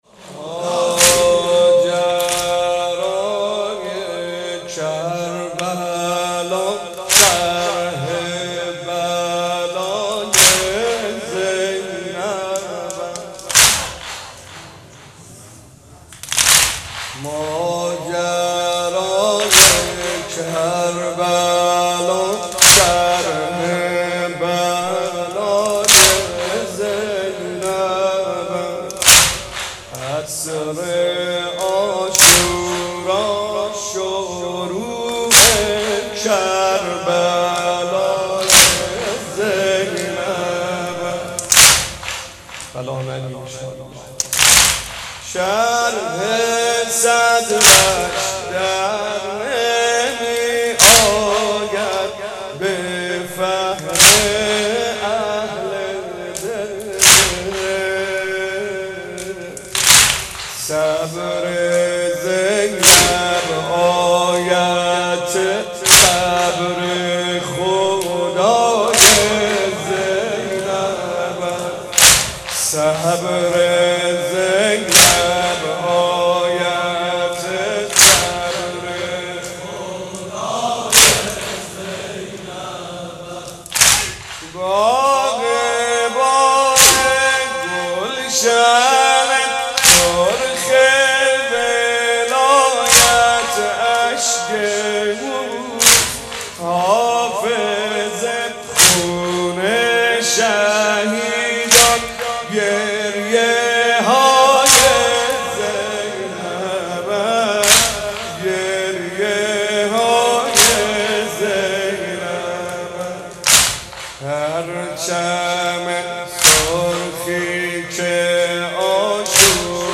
مداح
مناسبت : وفات حضرت زینب سلام‌الله‌علیها
قالب : سنگین